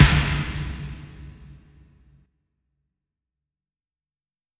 80s Snare (Paranoid).wav